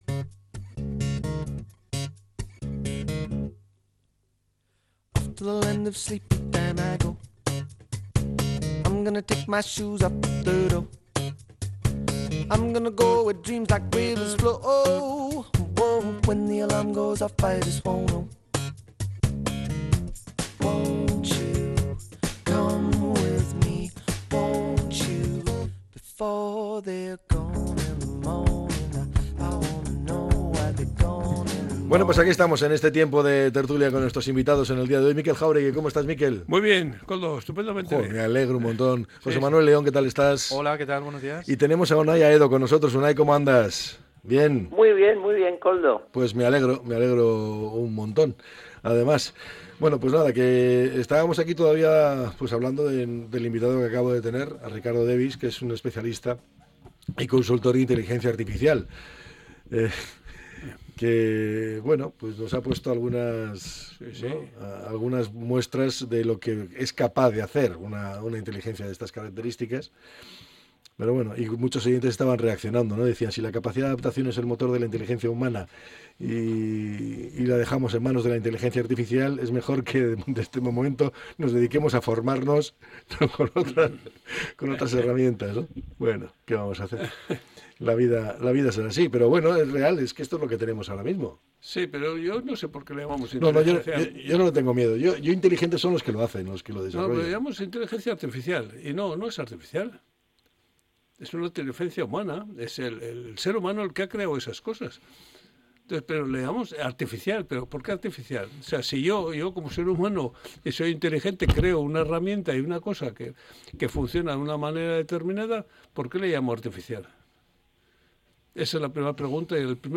La tertulia 05-02-25.